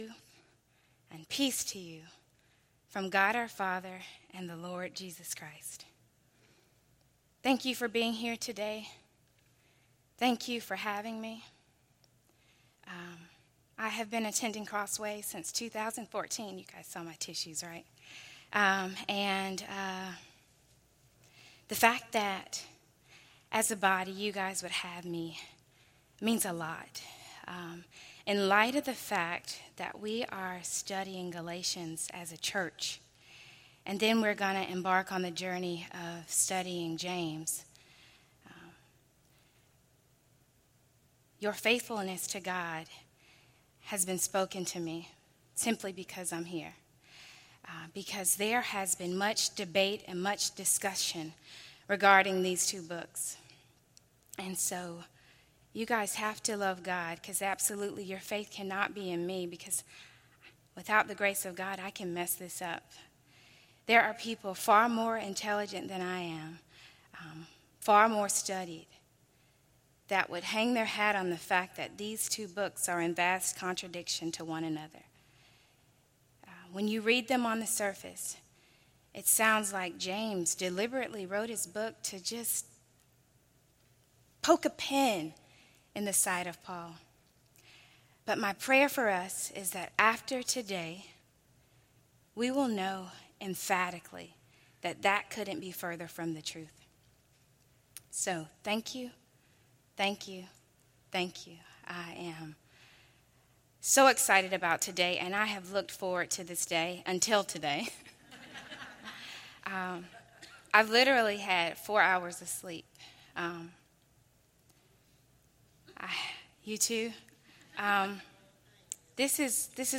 A message from the series "Women's Ministry."